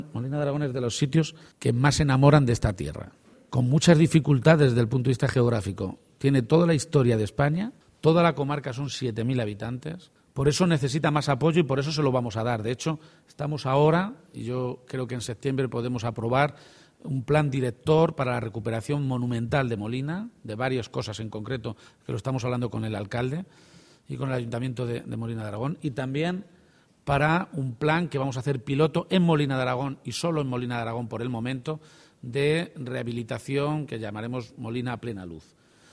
El presidente de Castilla-La Mancha, Emiliano García-Page, habla del Plan de Recuperación Patrimonial de Molina de Aragón.